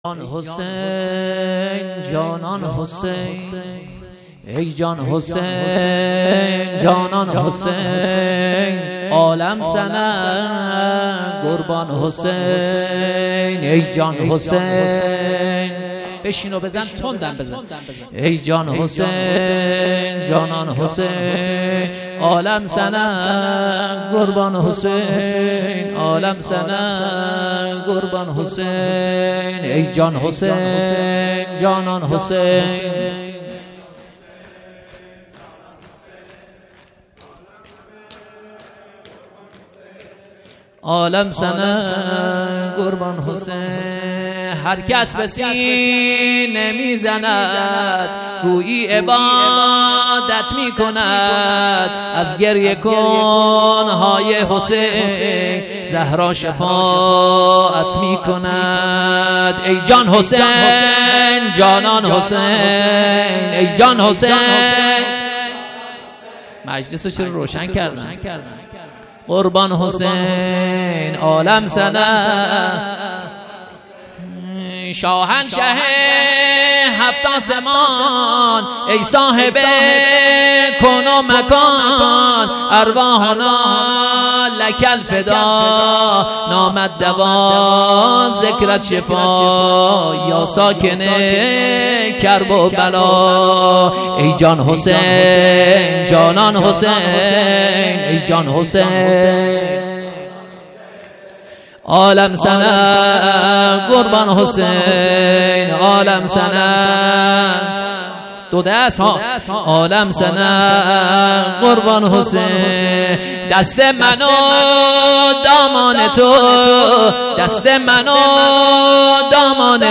شب چهارم محرم96 شور
دهه اول محرم 1396